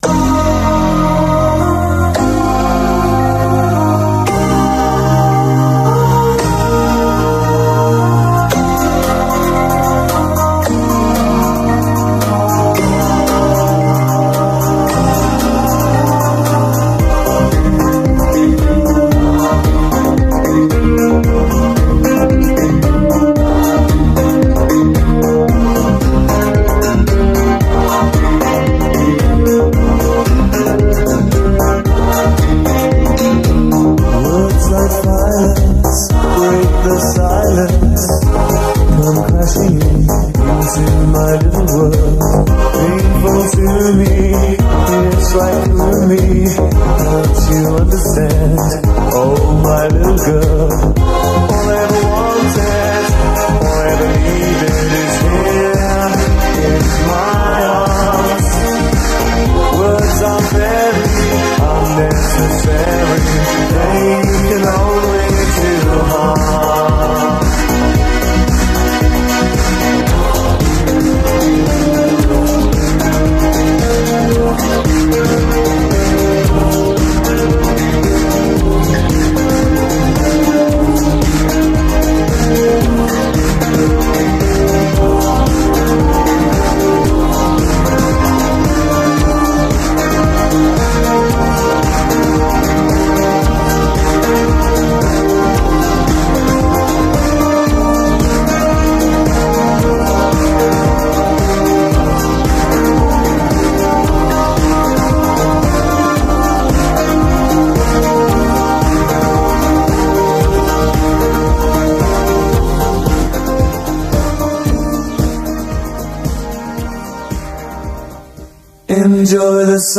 BPM113
Audio QualityCut From Video